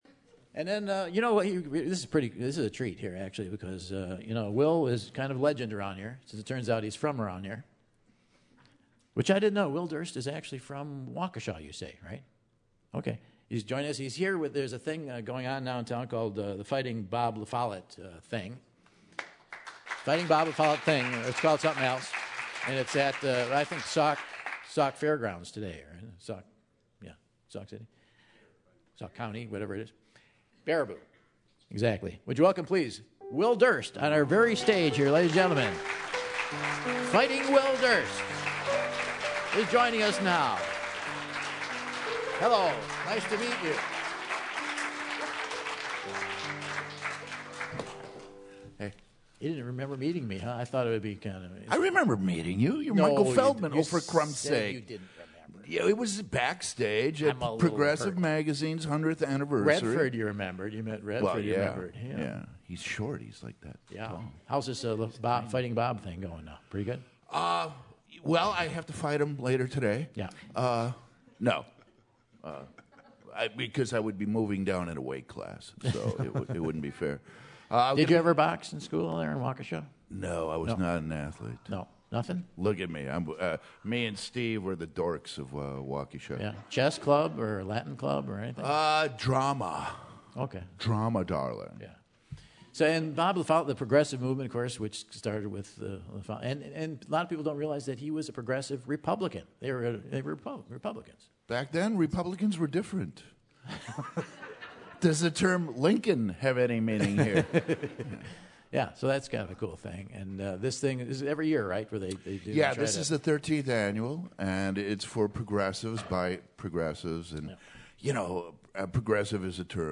While at the terrace Michael and Durst chat about politics, aging and more!